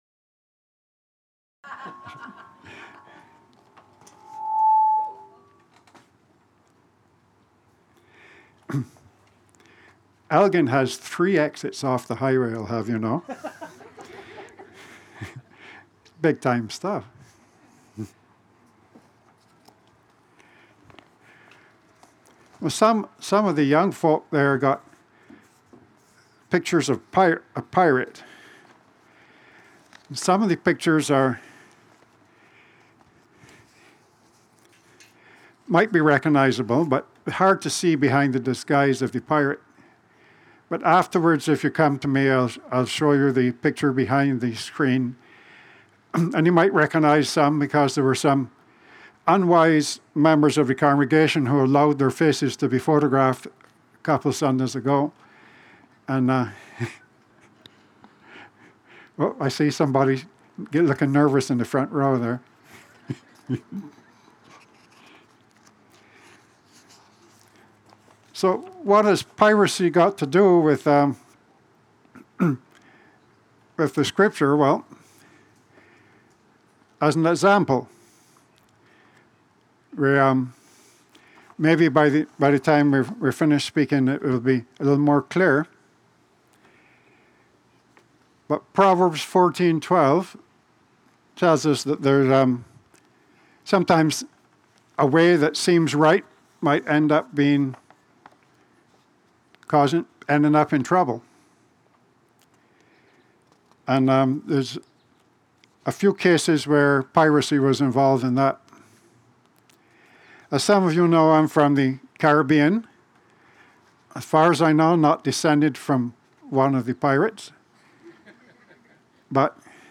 Acts 9:19-31 Service Type: Sermon